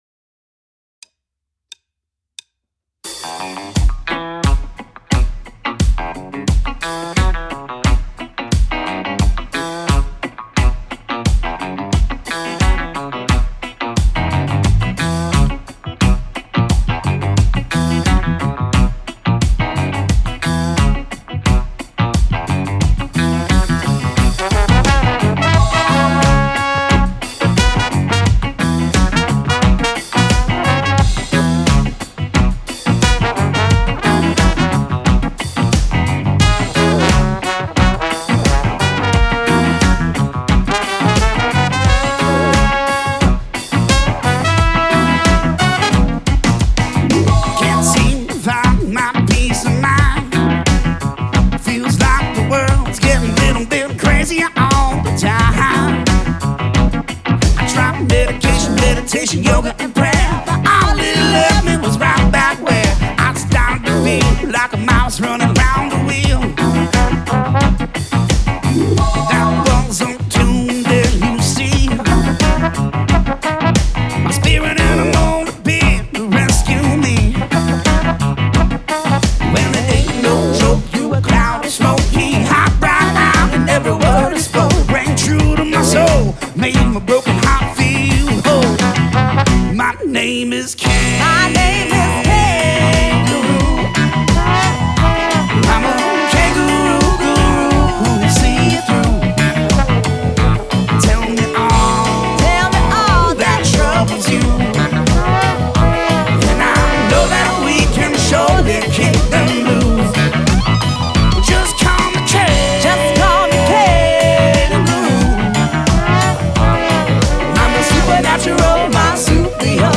Funk / West_end_Blend